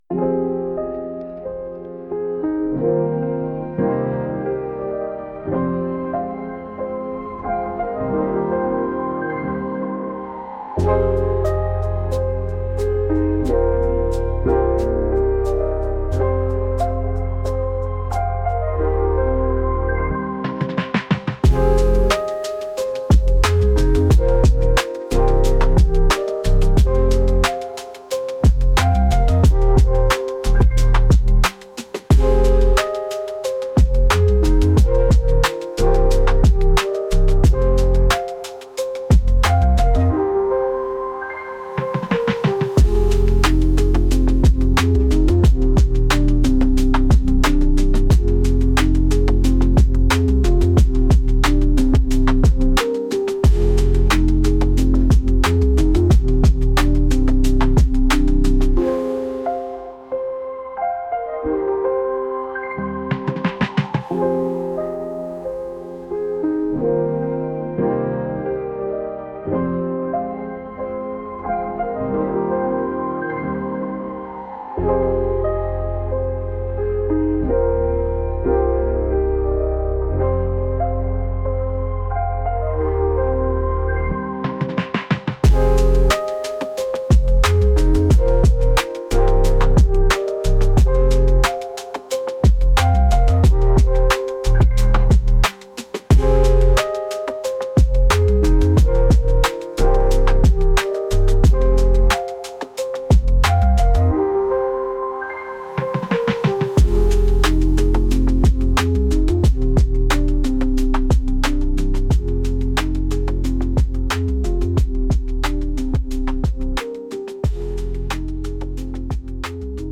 Music Genre: Plano Instrumental